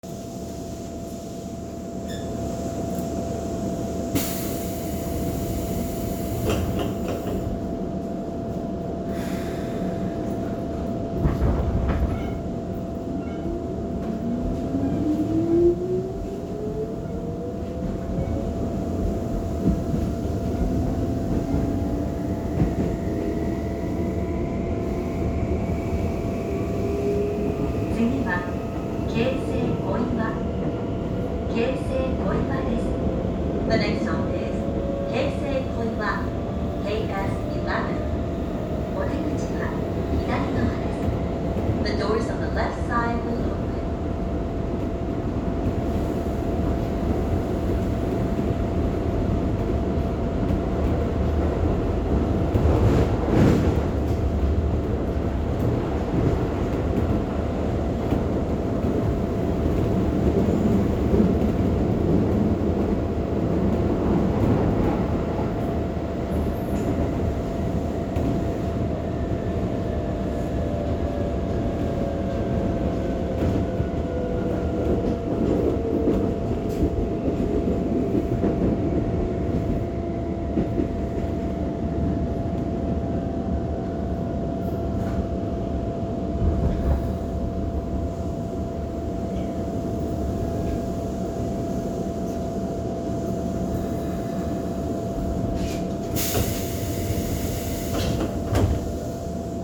・3600形走行音
〜自動放送導入後〜
3668Fを除いた3600形はチョッパ制御。基本的には3400形と同じ音です。
3688_Edogawa-Koiwa.mp3